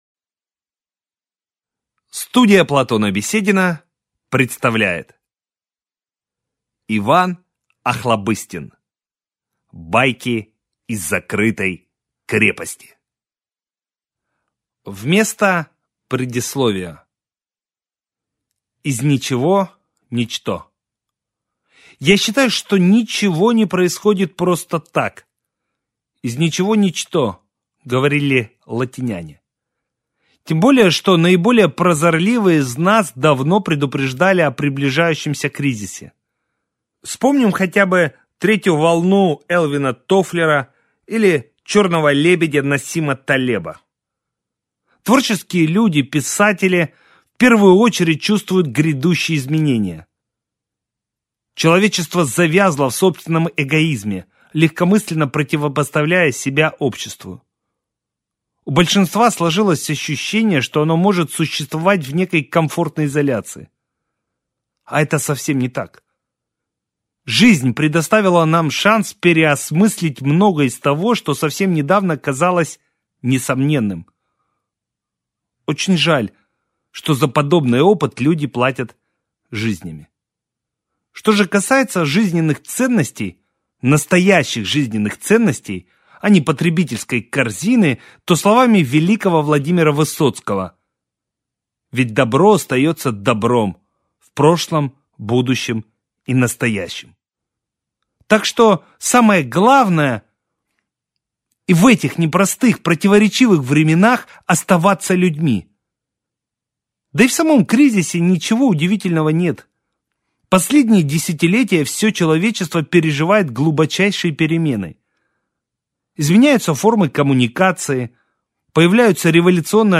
Аудиокнига Байки из закрытой крепости | Библиотека аудиокниг